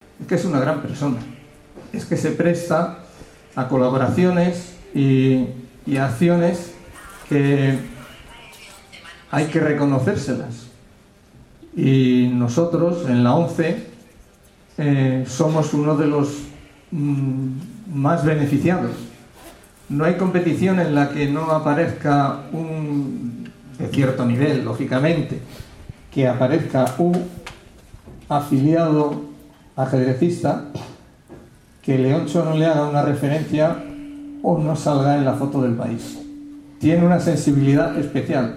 El experto en ajedrez Leontxo García ofrece una charla-conferencia en la Delegación Territorial de ONCE Madrid